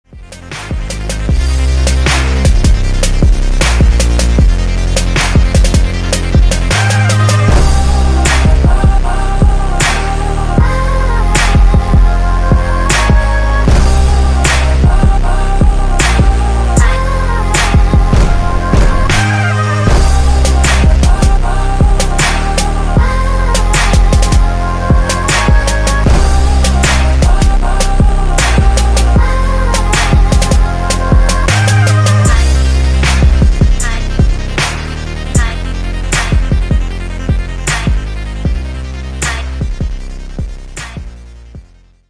спокойные
клубные
спокойный рингтон